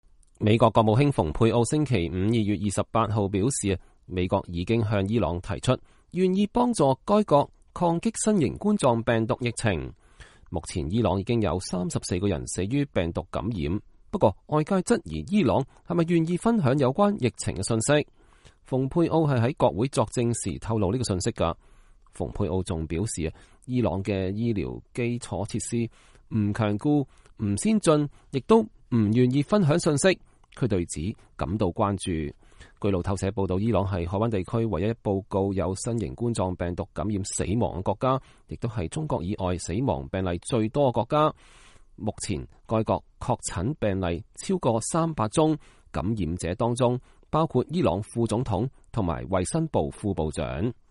美國國務卿蓬佩奧星期五（2月28日）在國會眾議院外交委員會作證。